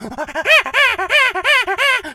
monkey_chatter_angry_12.wav